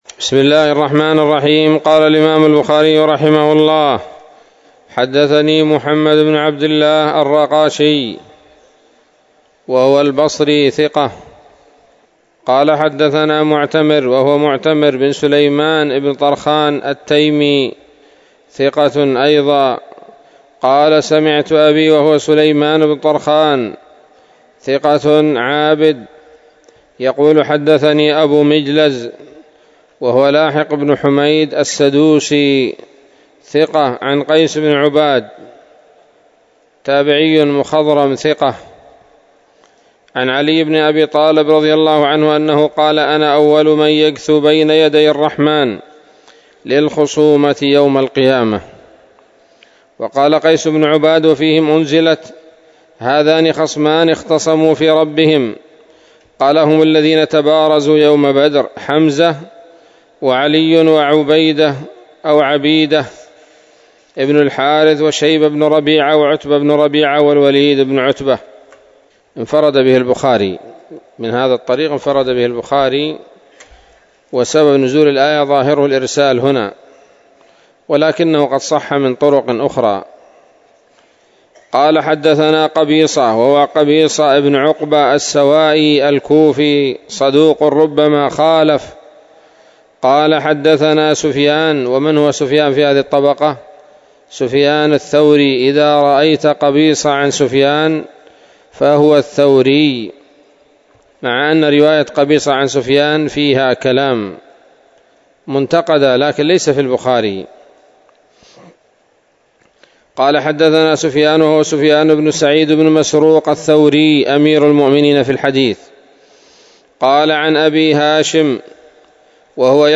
الدرس التاسع من كتاب المغازي من صحيح الإمام البخاري